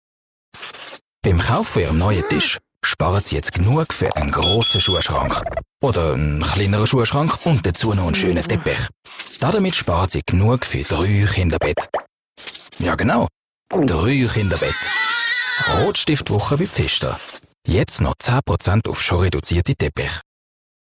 Werbung Schweizerdeutsch (ZH)